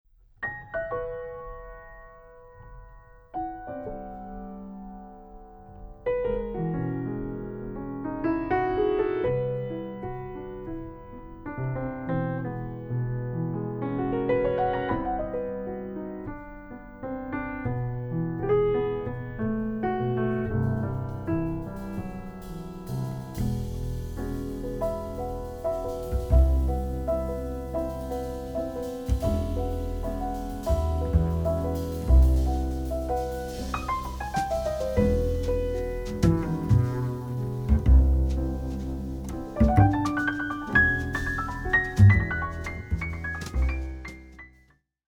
Recorded on July 13.2025 at Studio Happiness